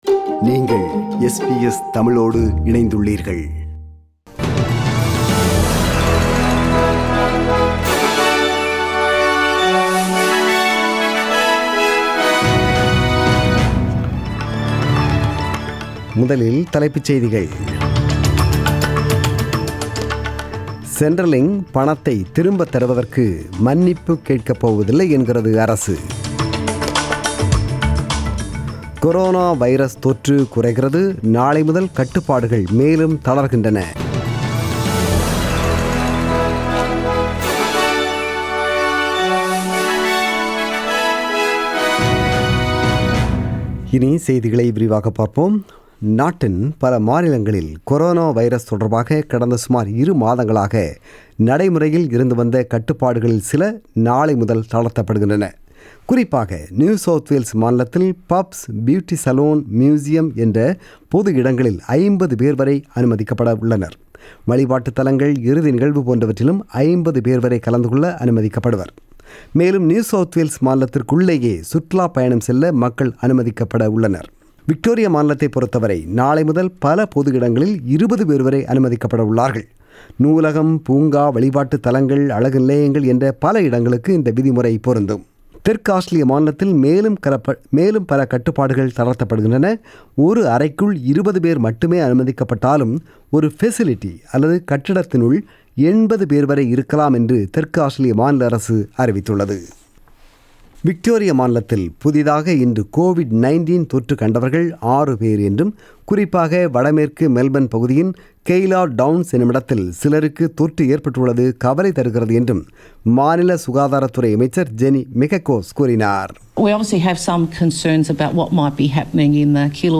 The news bulletin was broadcasted on 31 May 2020 (Sunday) at 8pm.